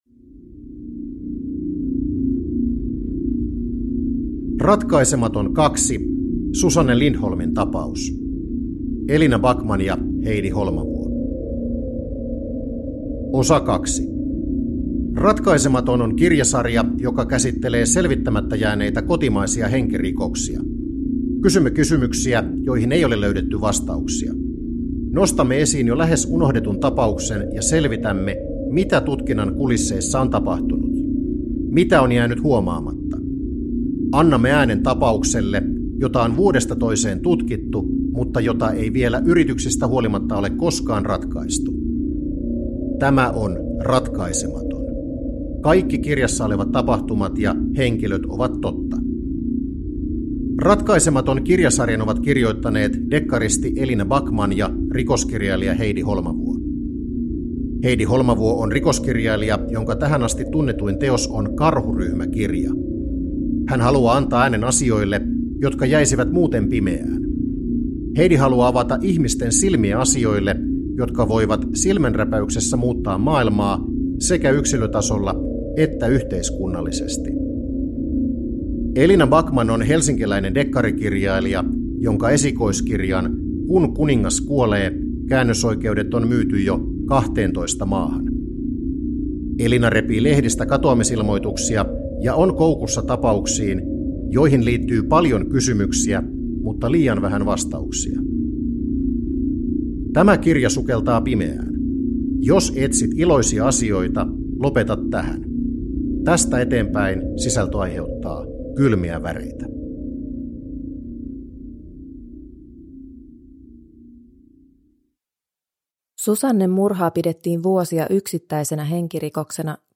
Ratkaisematon 2 (ljudbok) av Heidi Holmavuo